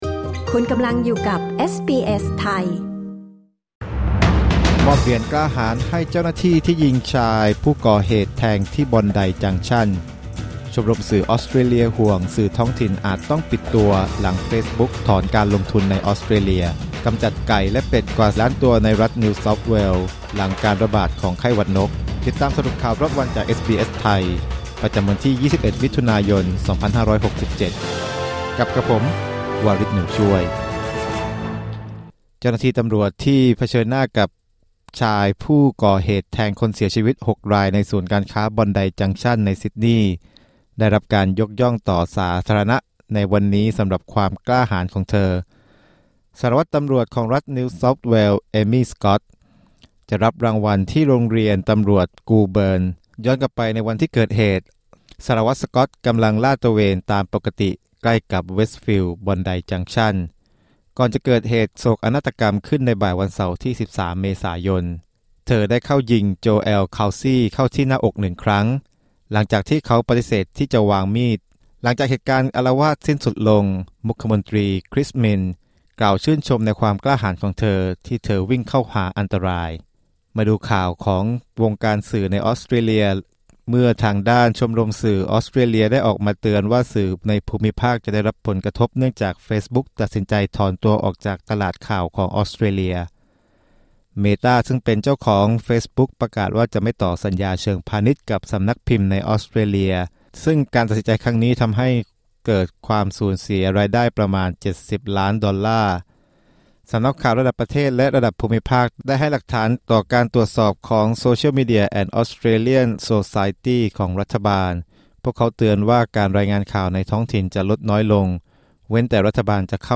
สรุปข่าวรอบวัน 21 มิถุนายน 2567
คลิก ▶ ด้านบนเพื่อฟังรายงานข่าว